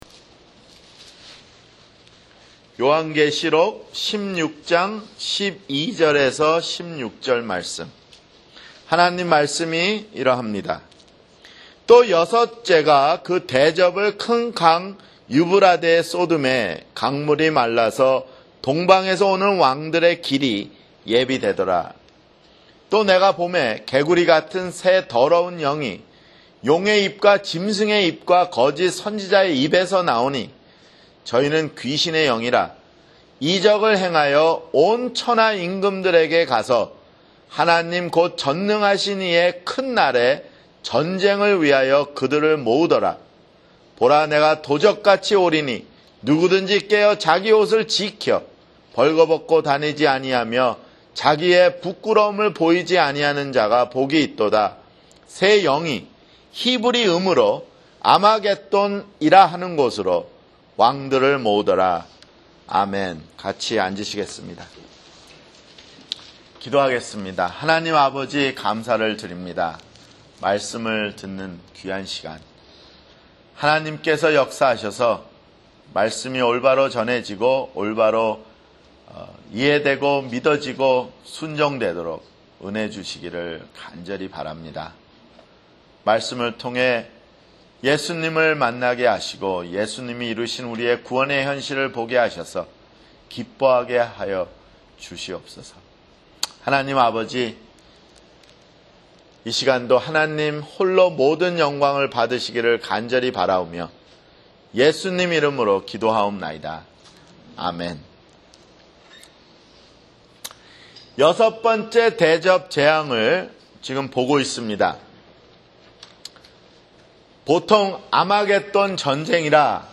[주일설교] 요한계시록 (62)